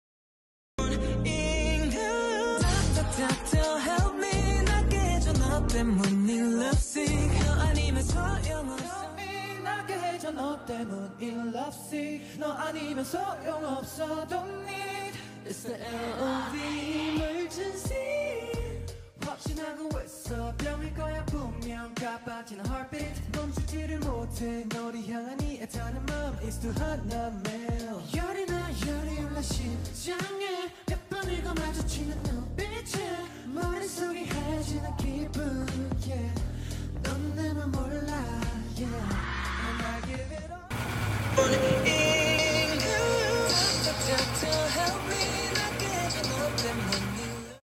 so minimalist!!